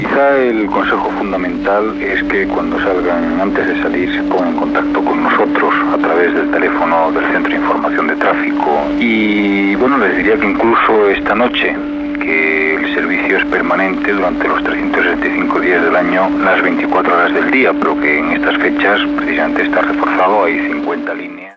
Consells de José Luis Martín , director de la Dirección General de Tráfico (DGT)
Informatiu